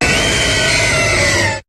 Cri de Kyogre dans Pokémon HOME.